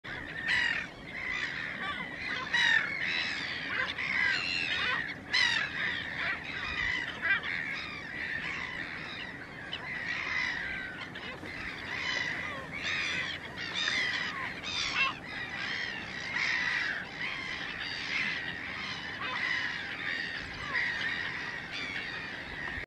gaviota capucho café
gaviota-capucho-cafe.mp3